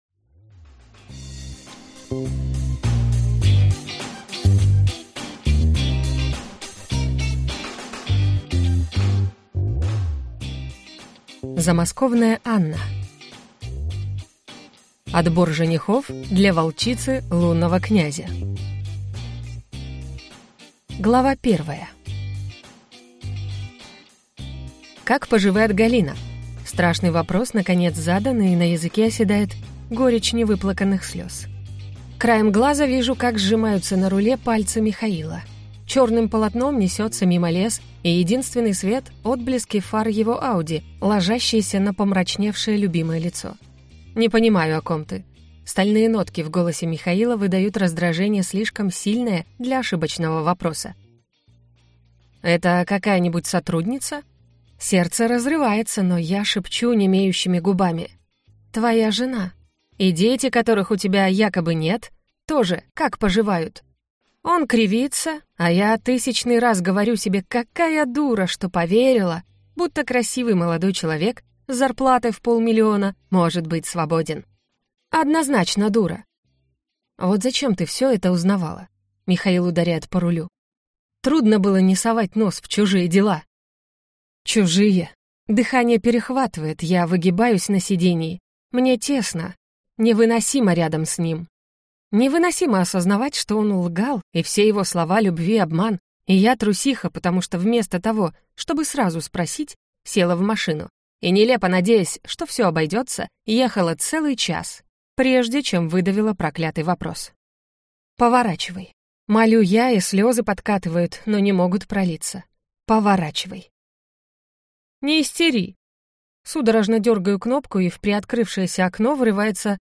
Aудиокнига Отбор женихов для волчицы лунного князя